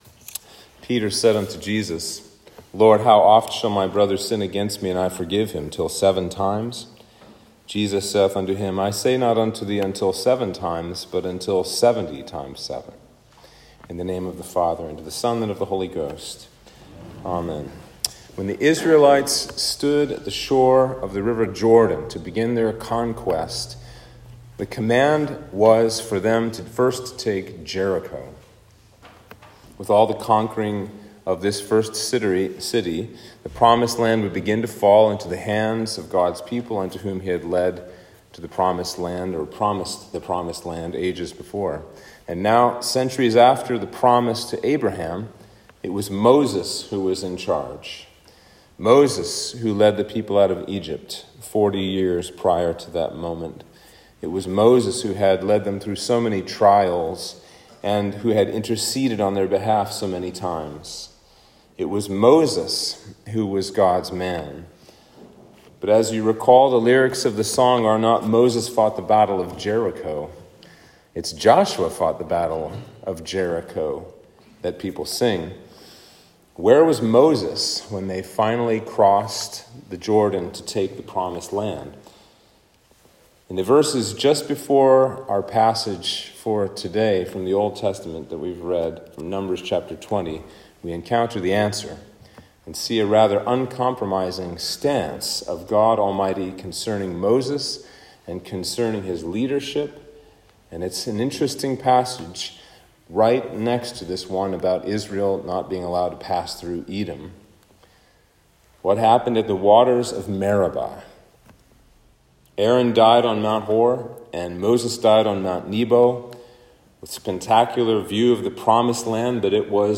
Sermon for Trinity 22